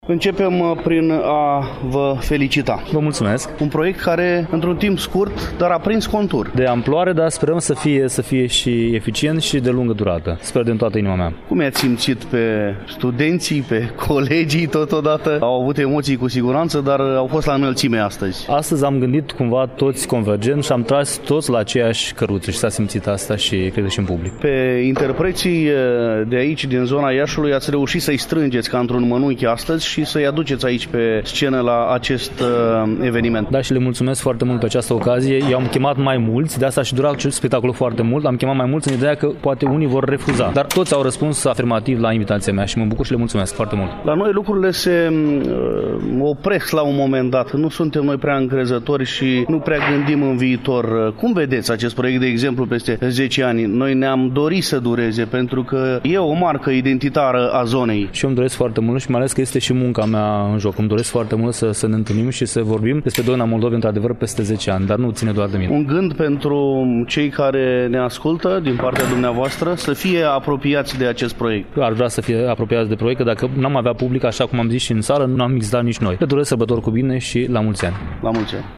Stimați auditori, în ediția de astăzi a emisiunii Tradiții, după cum bine știți, relatăm de la evenimentul – Doină, doină, cântec dulce – desfășurat, luni, 16 decembrie, începând cu ora 19:00, în Sala Unirii din incinta Cinematografului Victoria.